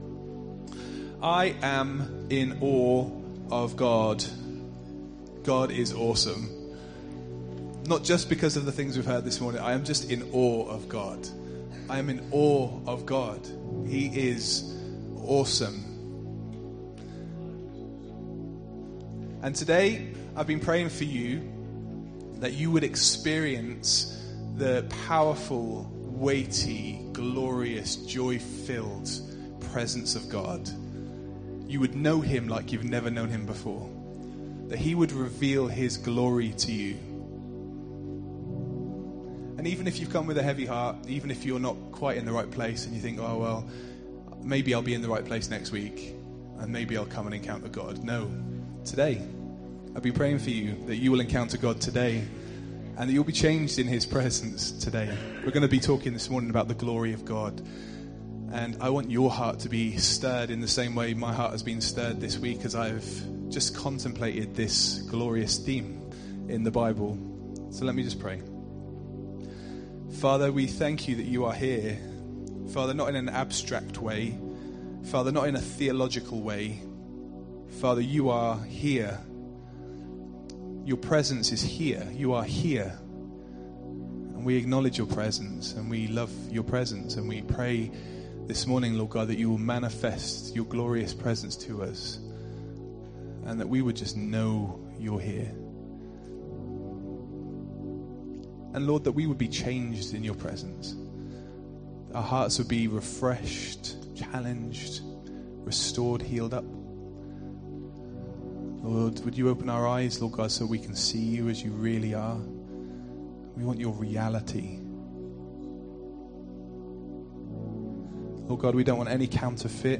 it is a must listen sermon.